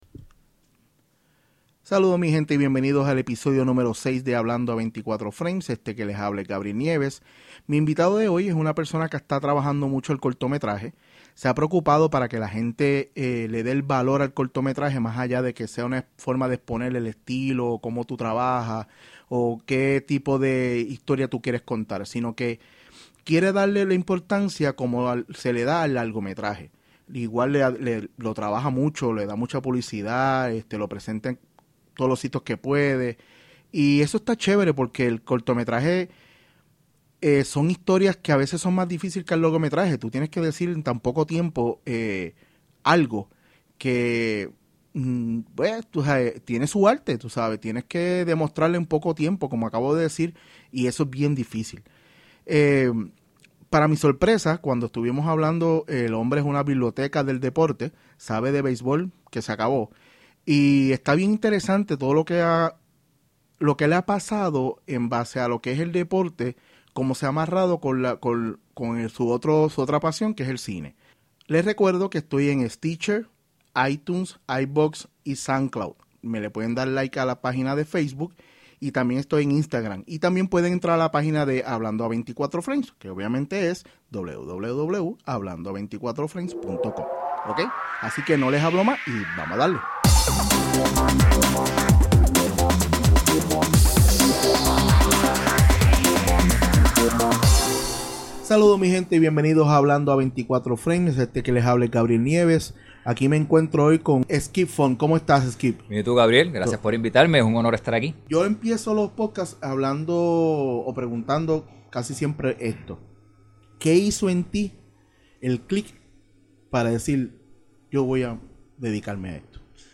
En este episodio numero 6 hablo con un realizador que se a dedicado hasta el momento a trabajar los cortometraje y darle el valor de importancia igual que una película y en la conversación me sorprendió lo mucho que sabe de la pelota profesional de PR.